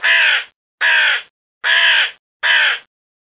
1 channel
crow.mp3